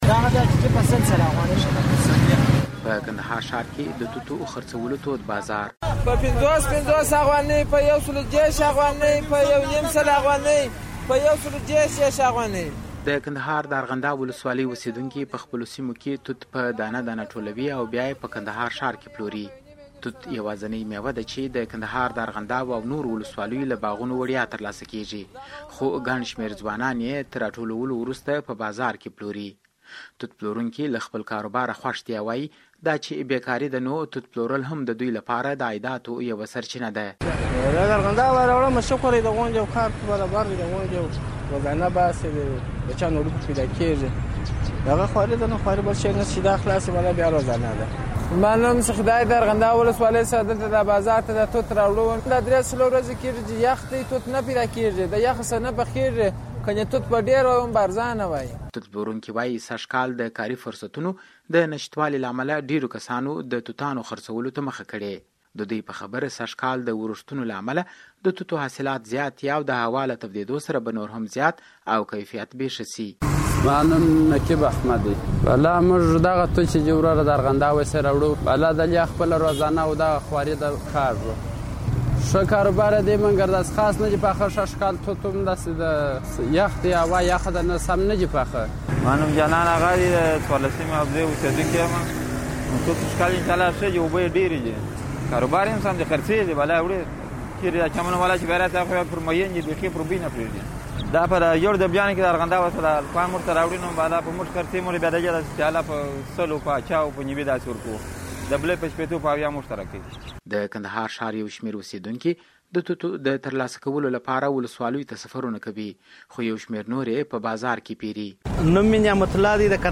د کندهار د توتانو راپور